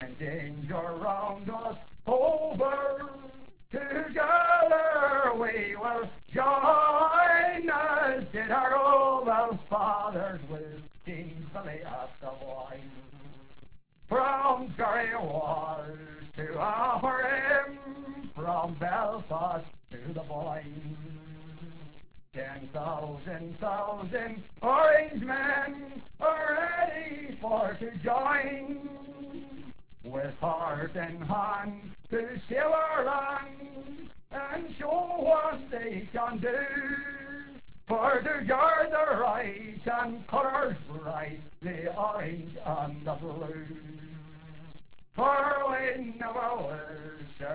ANNOUNCER: GO RIGHT AHEAD SIR RIGHT INTO THE MIKE DON'T BE SHY!